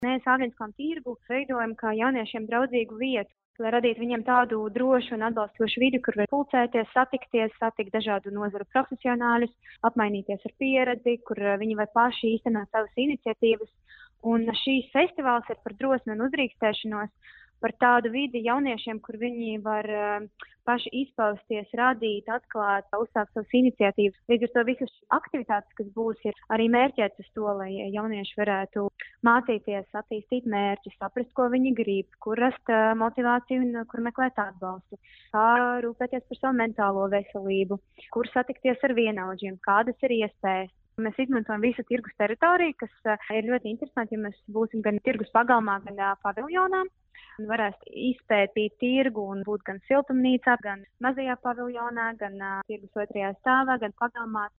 RADIO SKONTO Ziņās par jauniešu festivālu Āgenskalna tirgū